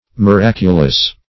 Miraculous \Mi*rac"u*lous\, a. [F. miraculeux. See Miracle.]
miraculous.mp3